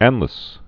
(ănlĭs)